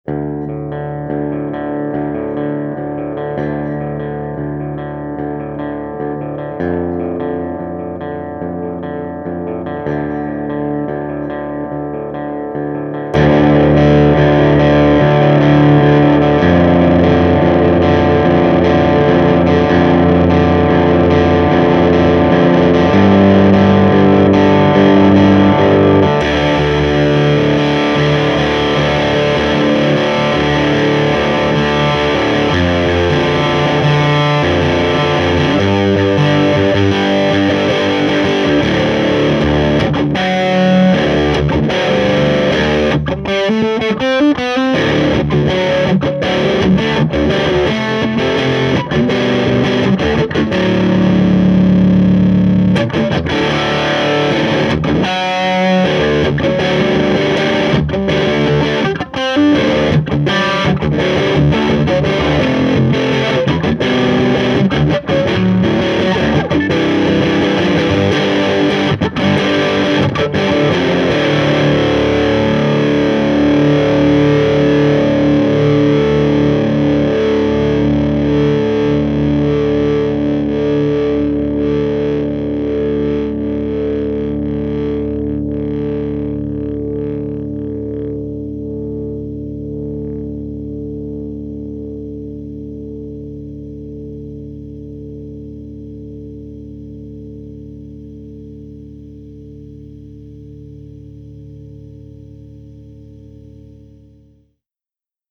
amp overdrive/crunch
these samples were recorded using either an ibanez rg560 loaded with duncan hot rails and jb junior pickups or a crappy strat knockoff with unknown pickups running a homebrew single ended amp (12ax7 and 6550) and 12" openback cabinet. miced with a 57.